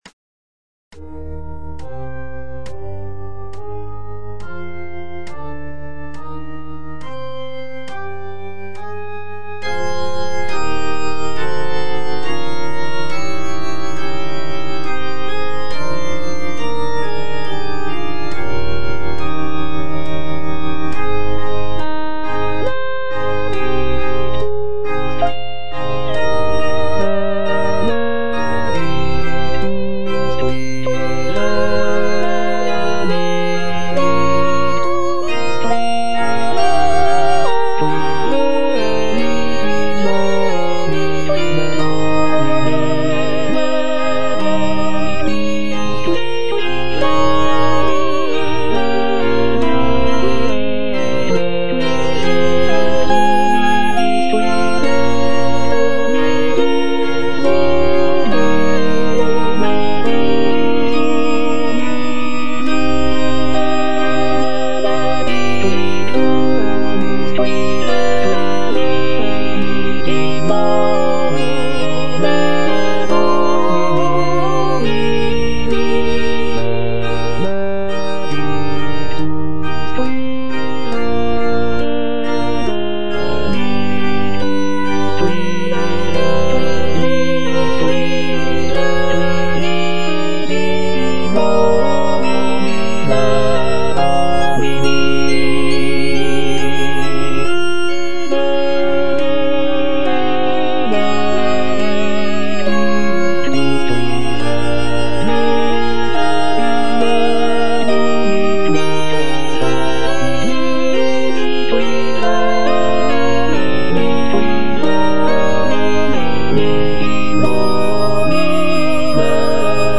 J.G. RHEINBERGER - MASS IN C OP. 169 Benedictus - Alto (Voice with metronome) Ads stop: auto-stop